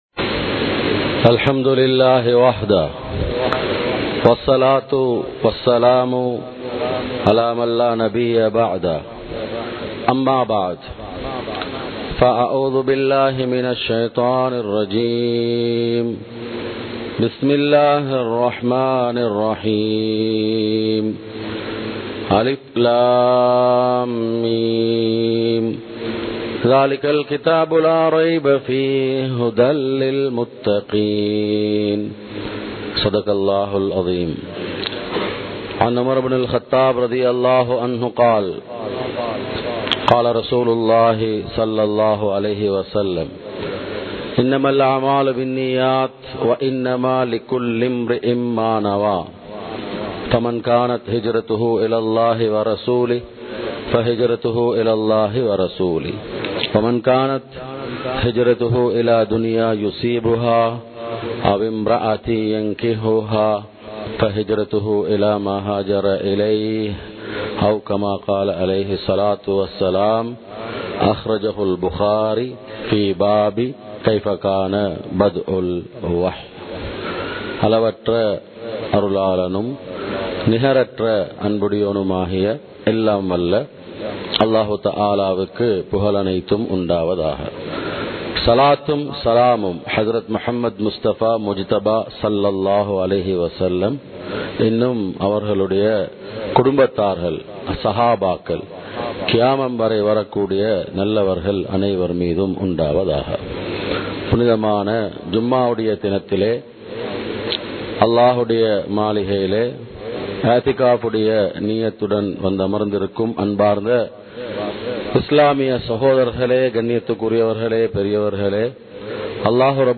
இப்றாஹீம்(அலை)அவர்களின் பண்புகள் | Audio Bayans | All Ceylon Muslim Youth Community | Addalaichenai
Muhiyadeen Jumua Masjith